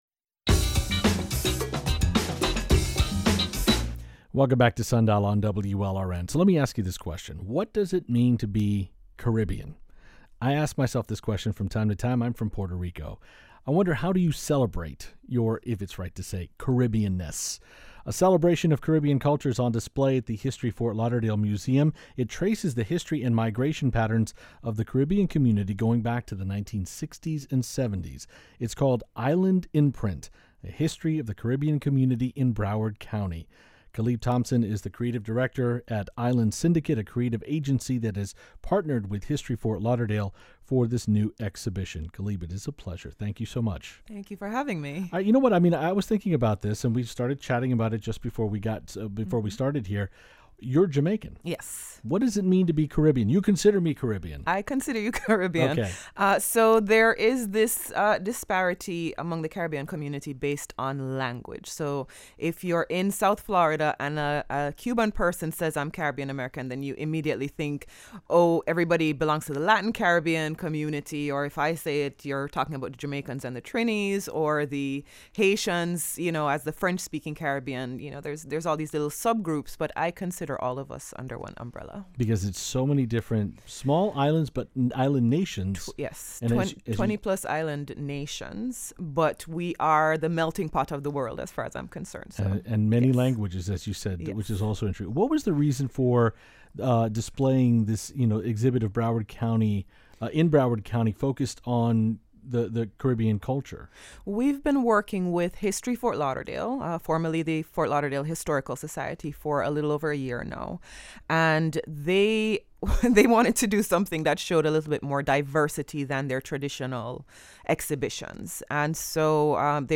about the new exhibition at History Ft. Lauderdale.